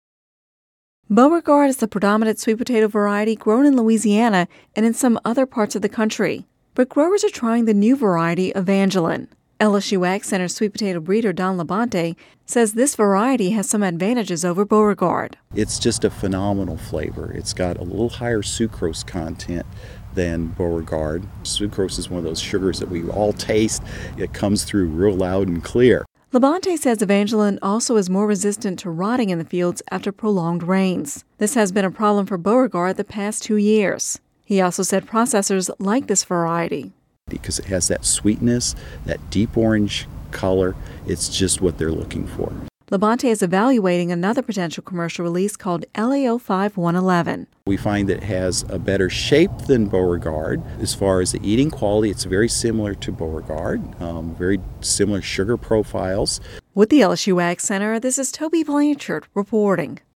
(Radio News 09/20/10) Beauregard is the predominant sweet potato variety grown in Louisiana and in some other parts of the country. But growers are trying the new variety Evangeline.